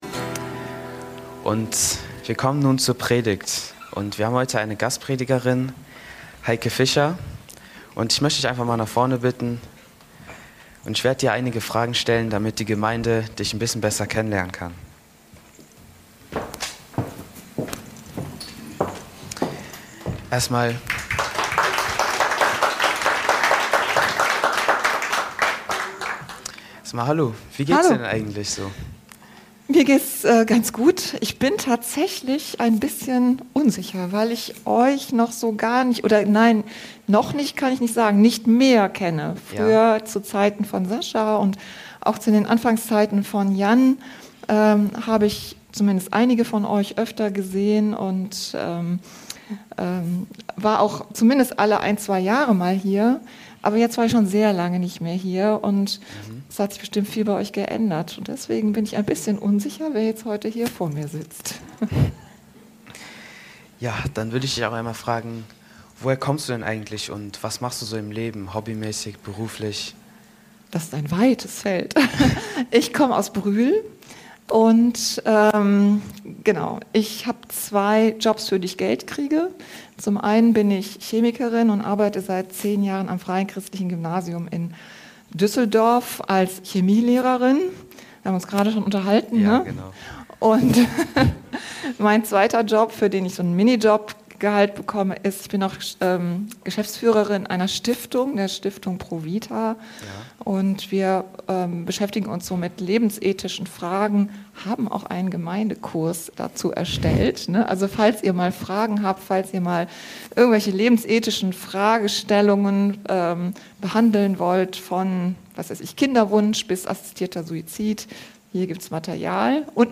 Predigt-Podcast von unterwegs FeG Mönchengladbach